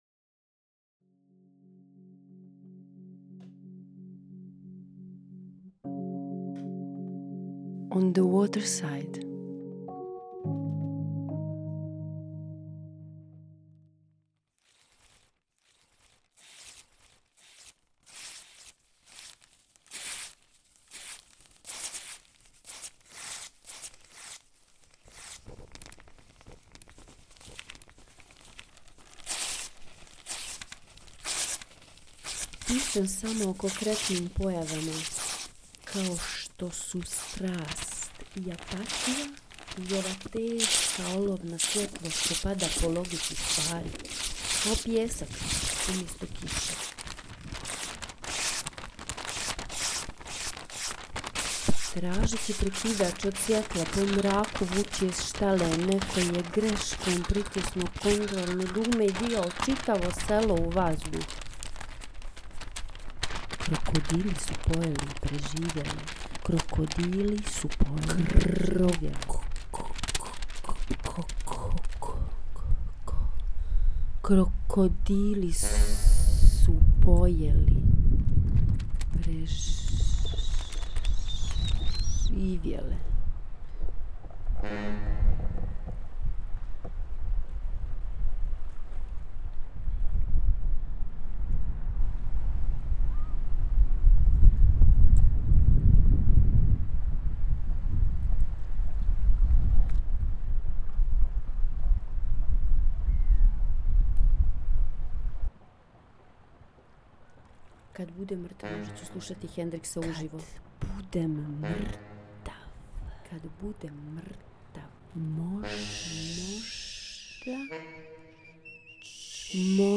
La portion court de la poésie sonore où tout se confonde. Amalgame de la lecture des fragments des ouvres en langue d’origine / Interprétation des écrits étranger différent, inexploré. Exploration de les sons dirty et les mélangeant avec des mélodies harmoniques. Chaque portion vous offre le son de la langue etranger superposés sur un tapis sonore crée exprès pour approcher l’ambiance.